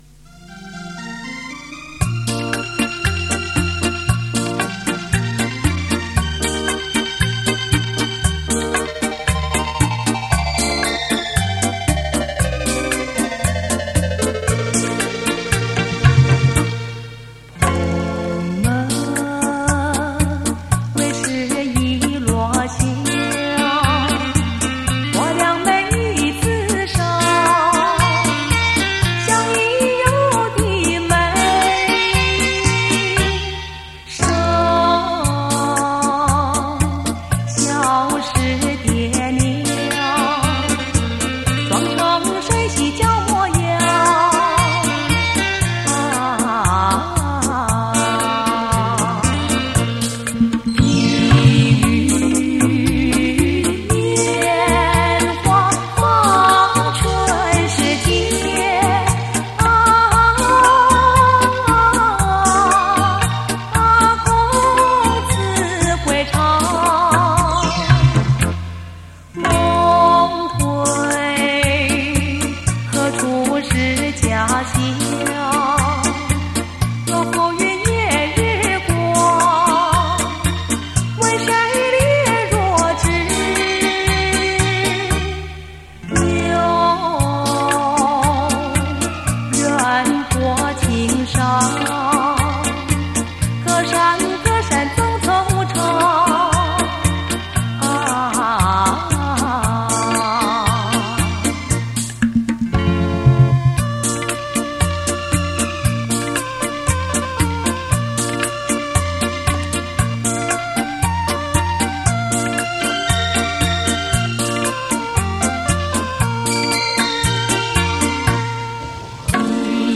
黄金双电脑鼓·特殊效果伴奏
CrO2 高级铬带 音质一路